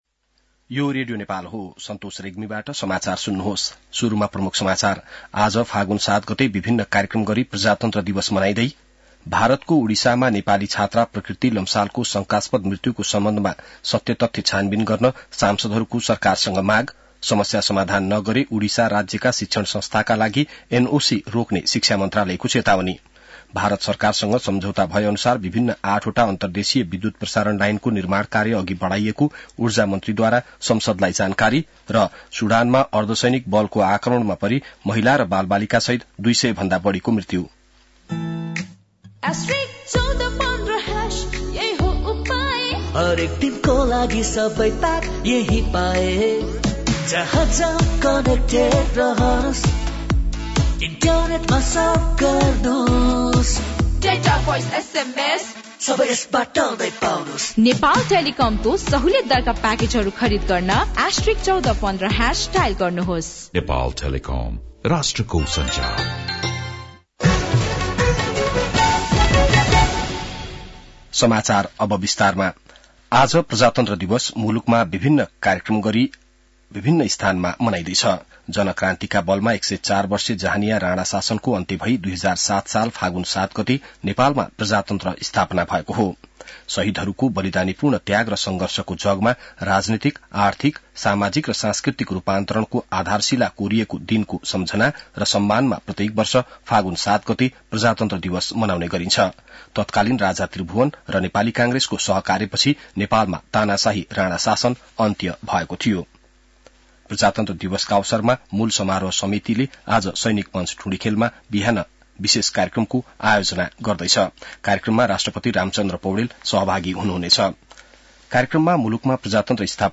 बिहान ७ बजेको नेपाली समाचार : ८ फागुन , २०८१